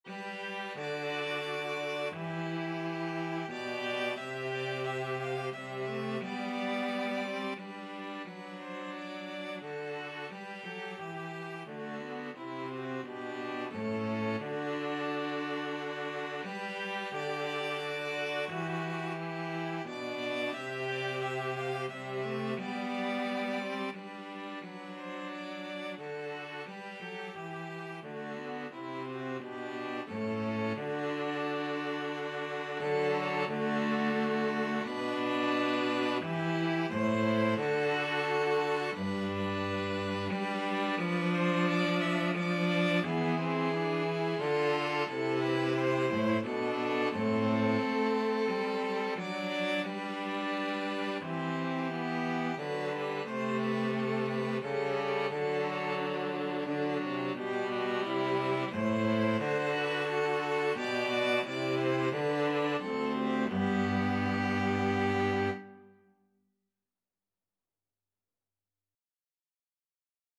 Free Sheet music for String trio
ViolinViolaCello
G major (Sounding Pitch) (View more G major Music for String trio )
Andante Cantabile ( = c.88)
3/4 (View more 3/4 Music)